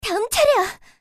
slayer_f_voc_skill_changeflying.mp3